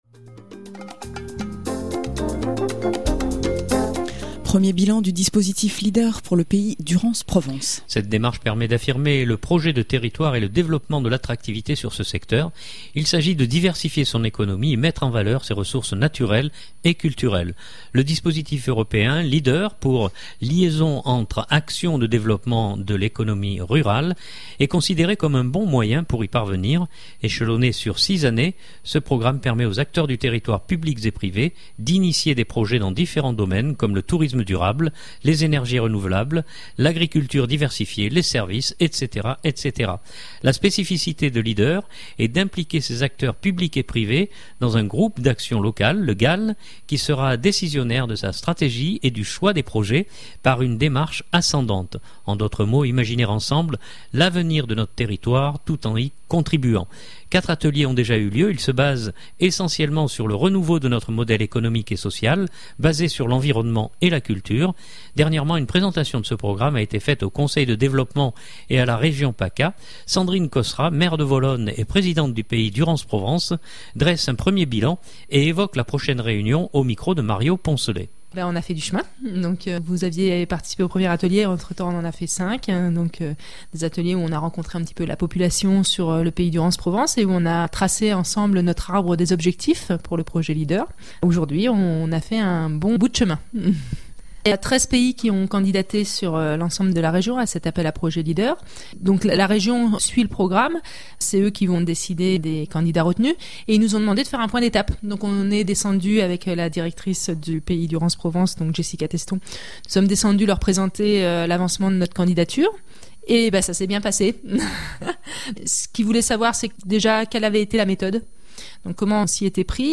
Sandrine Cosserat, Maire de Volonne et Présidente du Pays Durance Provence dresse un premier bilan et évoque la prochaine réunion